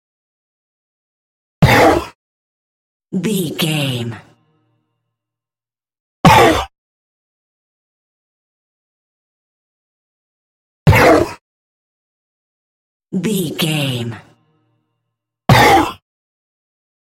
Monster creature puf x4
Sound Effects
heavy
intense
dark
aggressive